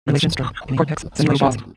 Spaceship_fx_08.mp3